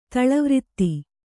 ♪ taḷa vritti